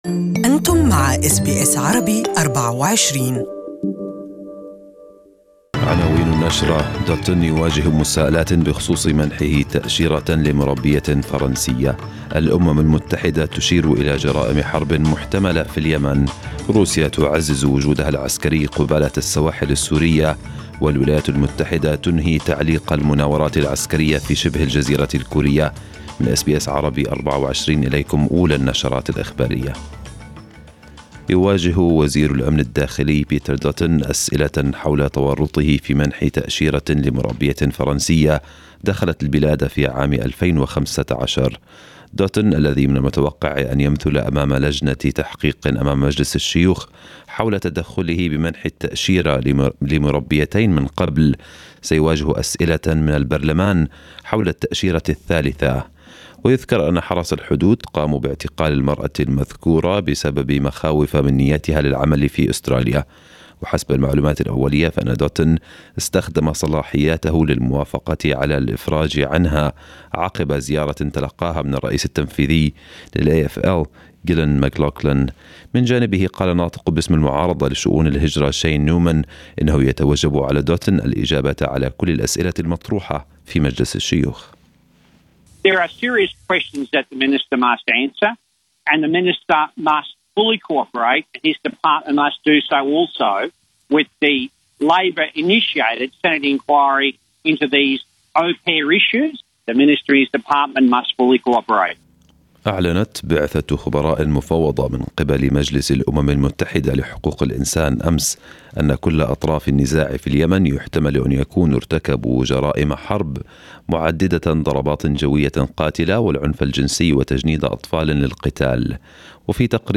وزير الأمن الداخلي يواجه أسئلة متعلقة بمنحه تأشيرة لمربية فرنسية، وأخبار أخرى في نشرة الأنباء الصباحية.